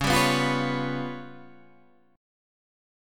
C#7b5 chord